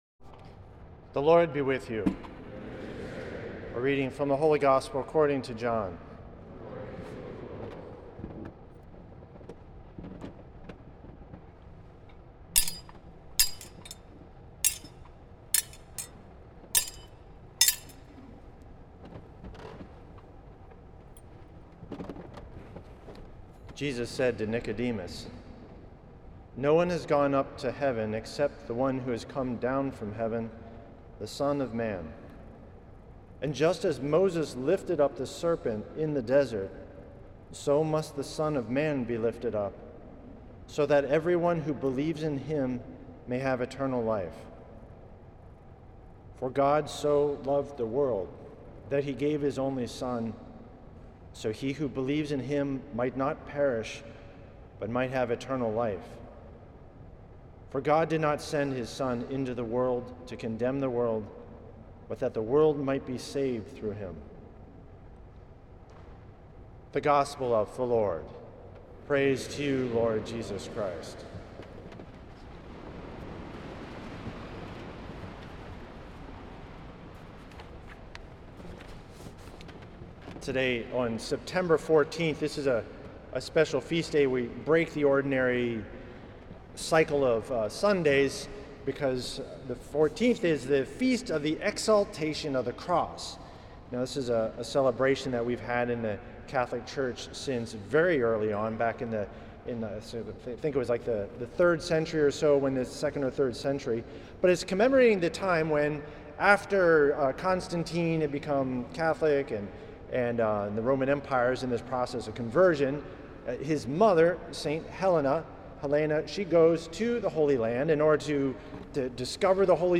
Homily
at St. Patrick’s Old Cathedral in NYC